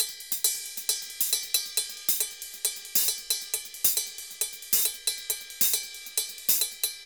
Ride_Merengue 136-1.wav